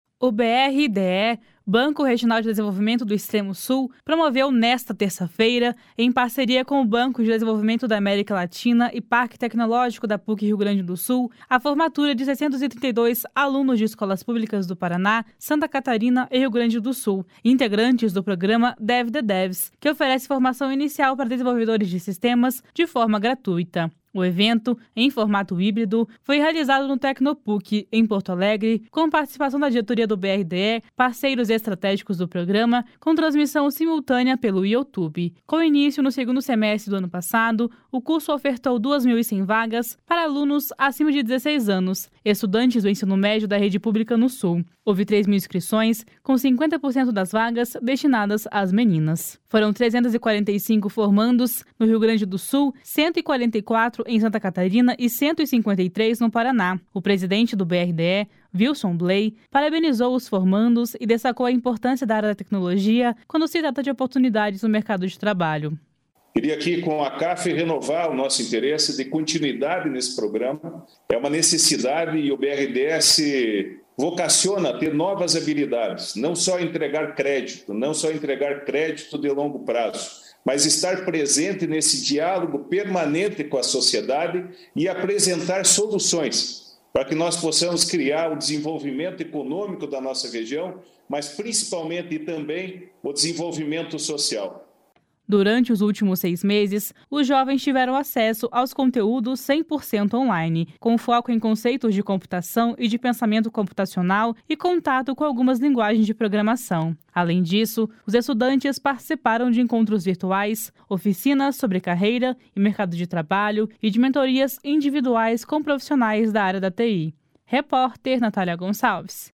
O presidente do BRDE, Wilson Bley, parabenizou os formandos e destacou a importância da área da tecnologia quando se trata de oportunidades no mercado de trabalho. // SONORA WILSON BLEY //